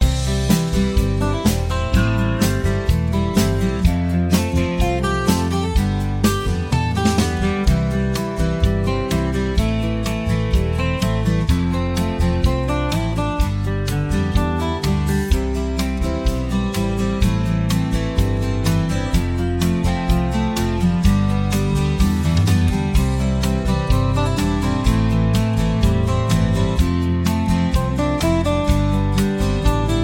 two instrumentals